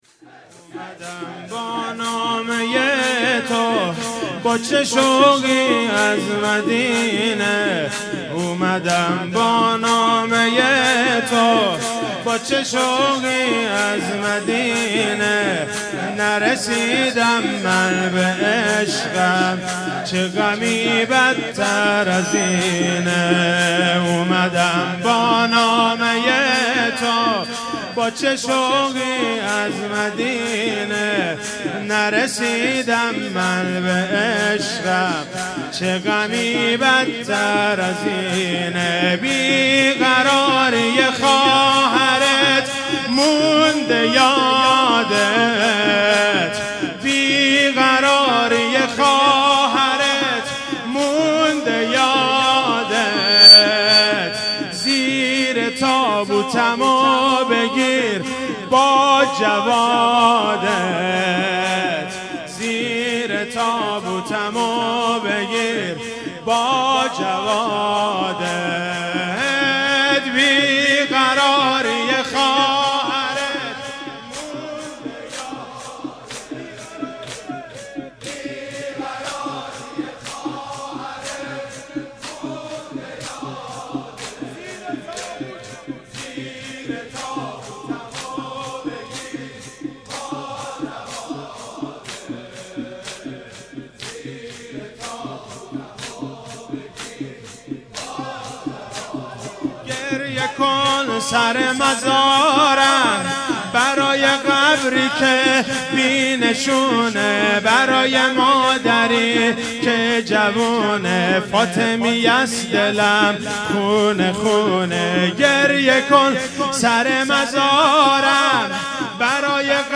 شهادت حضرت معصومه سلام الله علیها96 - زمینه - اومدم با نامه ی تو
شهادت حضرت معصومه سلام الله علیها